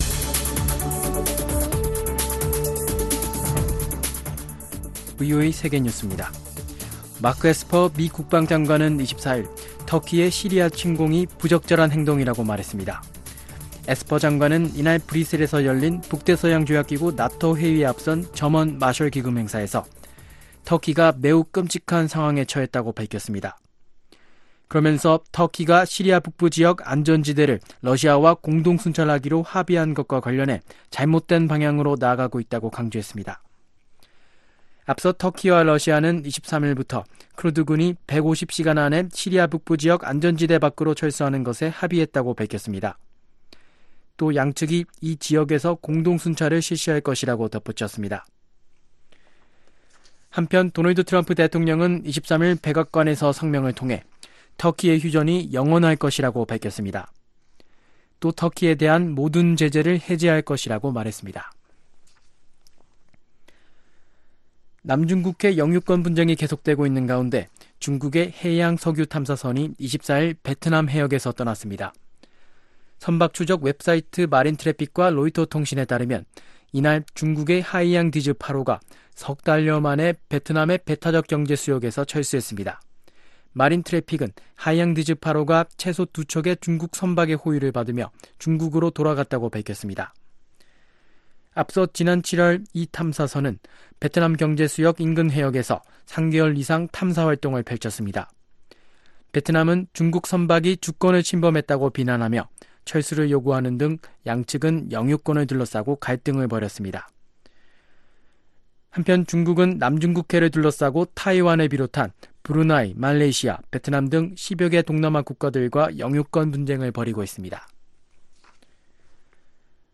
VOA 한국어 아침 뉴스 프로그램 '워싱턴 뉴스 광장' 2019년 10월 25일 방송입니다. 북한 김계관 외무성 고문이 미-북 정상간 친분을 언급하며 보다 좋은 방향으로 관계를 진전시킬 수 있는 동력이 되길 바란다며 미국의 태도 변화를 요구했습니다. 북한 식량난의 가장 큰 책임은 북한 정권에 있다고 유엔 북한인권 특별보고관이 지적했습니다.